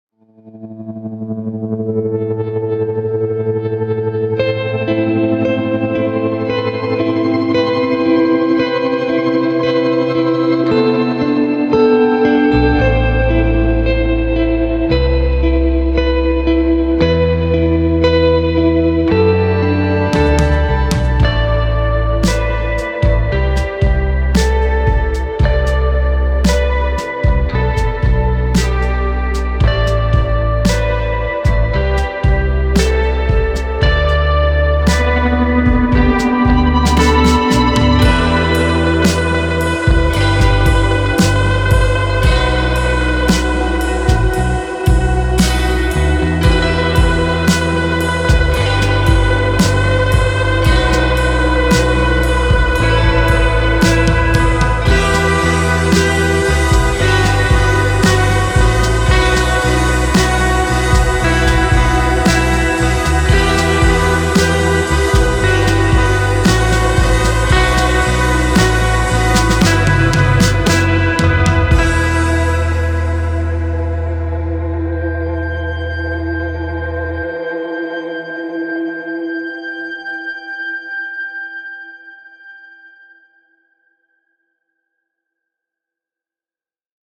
Mellow electric guitars with warmth and emotion
• Two classic vintage amps with lively spring reverbs
Audio demos
Indie vibes meet moody, twangy perfection